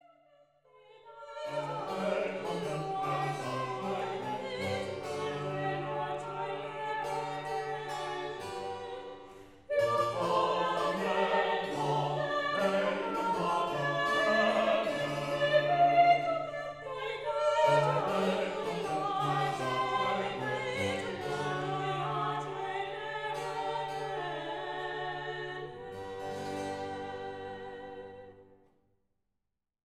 performed with expert accuracy